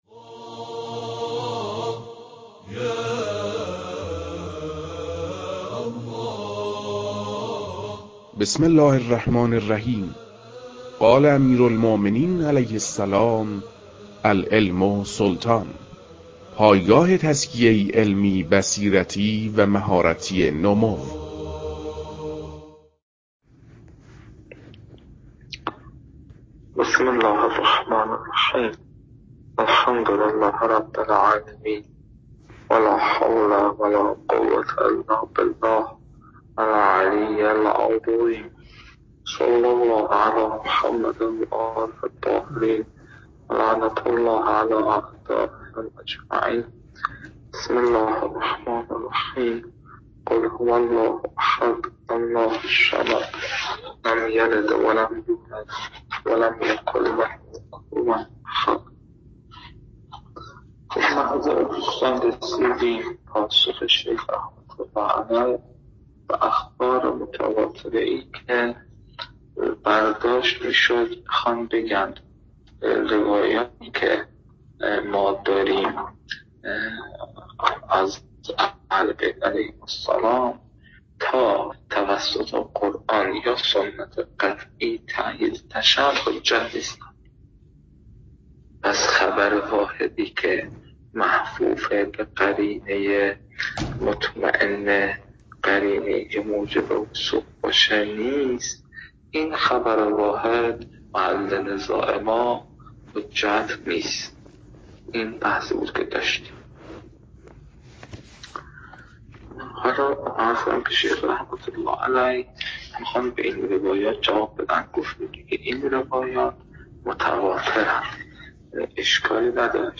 (کیفیت صوت به‌دلیل بیماری استاد کمی ضعیف است.)